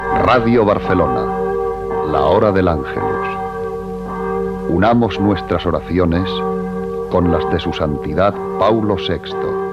Identificació de la ràdio i del programa i invitació a unir-se a l'oració amb el Sant Pare Pau VI